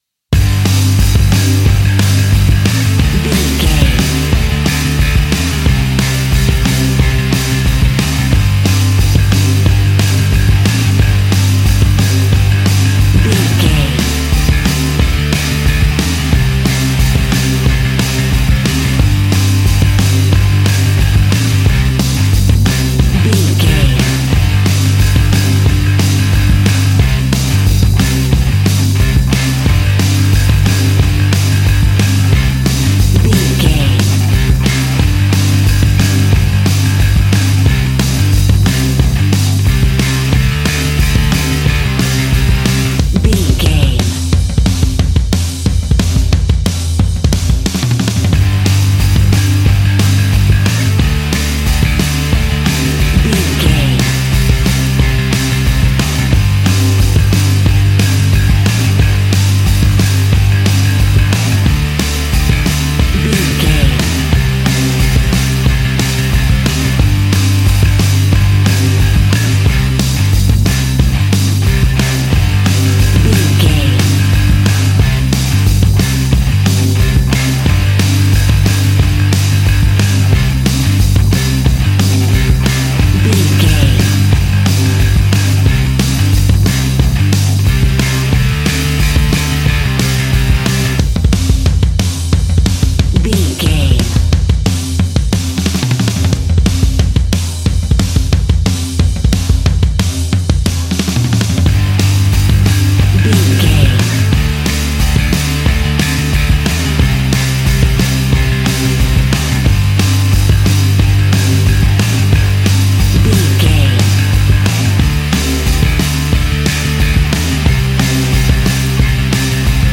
Fast paced
Ionian/Major
hard rock
blues rock
distortion
punk metal
rock instrumentals
Rock Bass
Rock Drums
distorted guitars
hammond organ